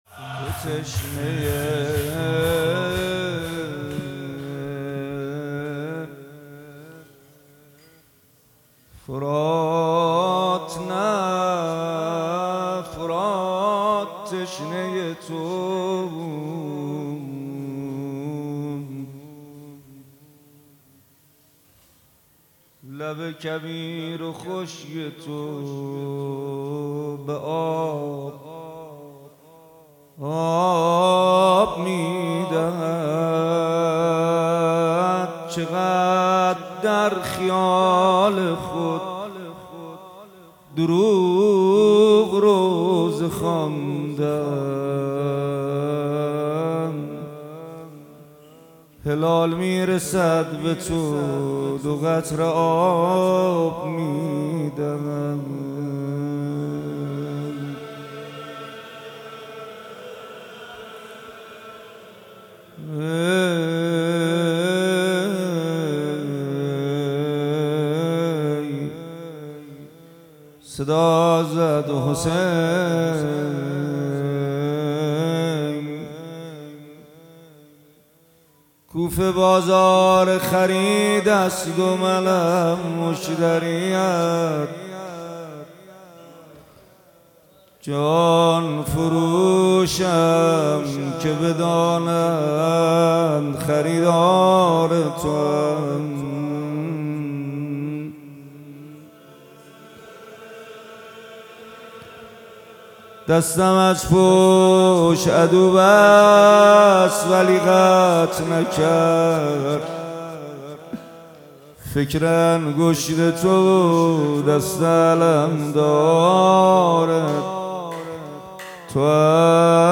مداحی تو تشنه‌ی فرات نه فرات تشنه‌ی تو بود توسط محمد حسین پویانفر در مجلس ریحانه النبی | 24 خرداد | 1403 اجراشده. مداحی به سبک روضه اجرا شده است.